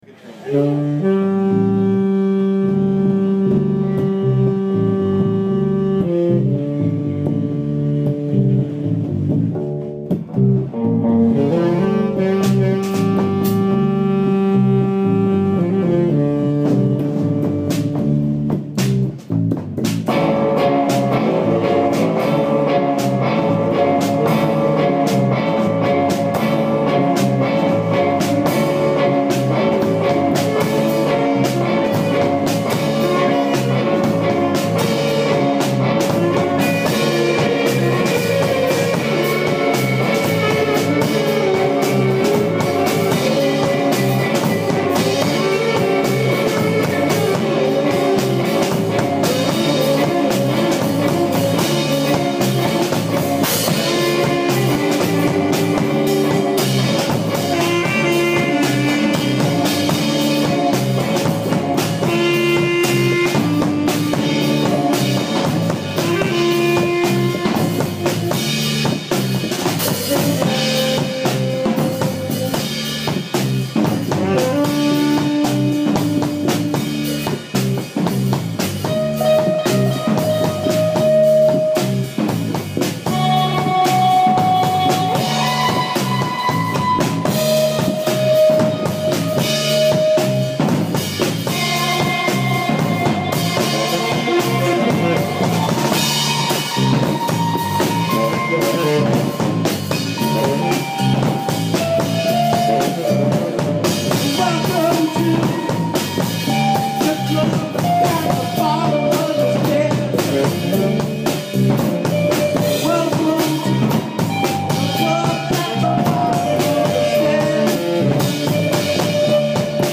ALL MUSIC IS IMPROVISED ON SITE
Delusions Of Grandeur voice
flute/voice
drums
sitar